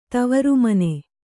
♪ tavaru mane